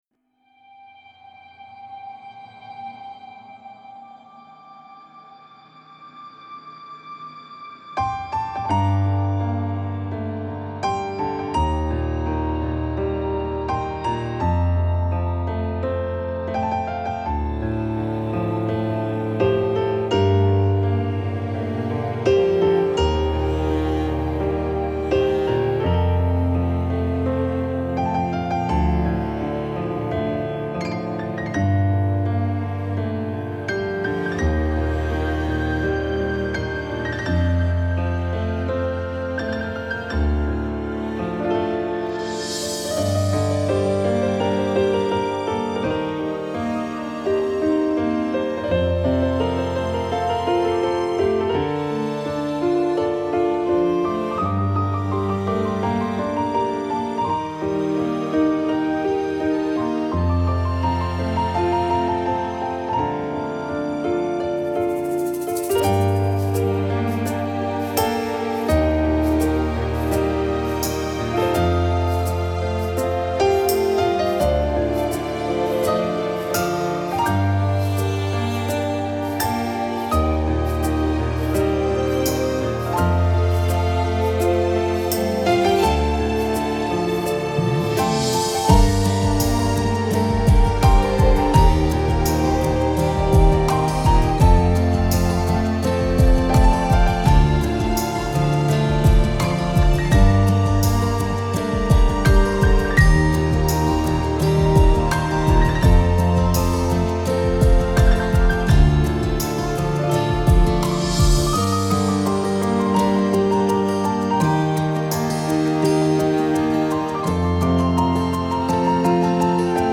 پیانو